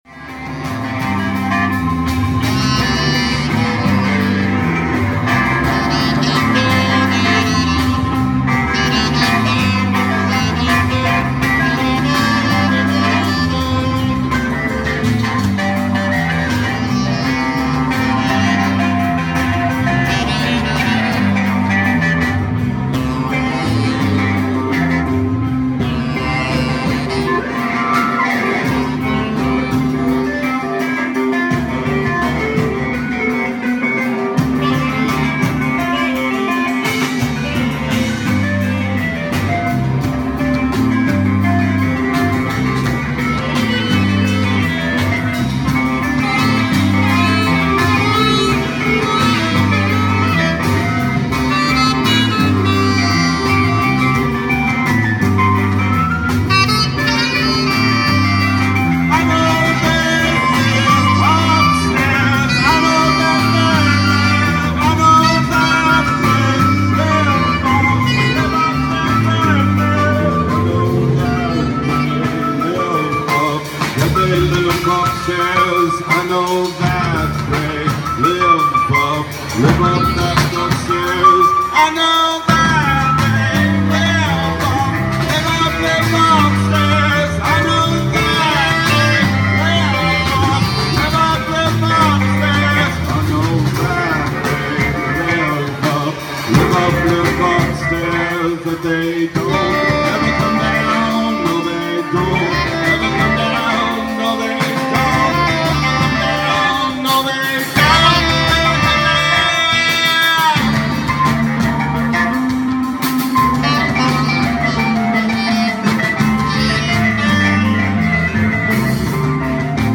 ALL MUSIC IS IMPROVISED ON SITE
The Men Upstairs guitar/voice
keys/moog
flute
drums